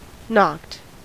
Ääntäminen
Ääntäminen US Tuntematon aksentti: IPA : /nɔkt/ Haettu sana löytyi näillä lähdekielillä: englanti Käännöksiä ei löytynyt valitulle kohdekielelle.